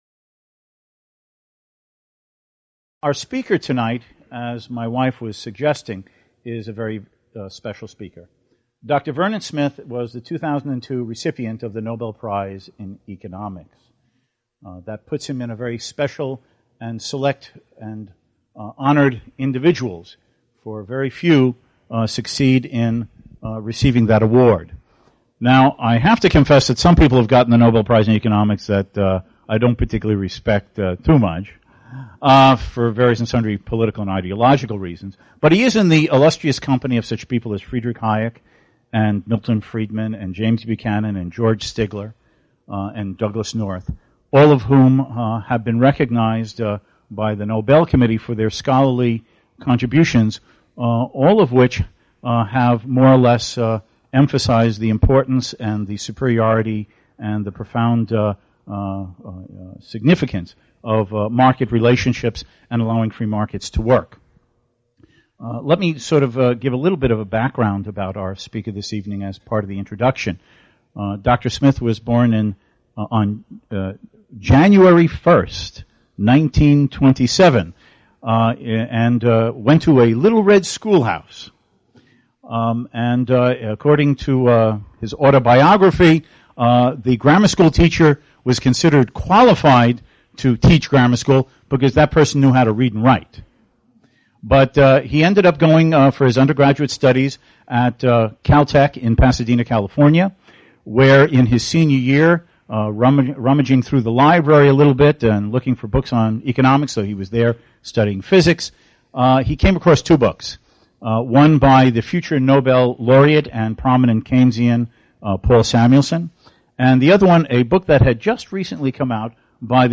The following is abridged from a speech delivered at “Evenings at FEE” in September 2005.